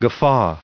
1579_guffaw.ogg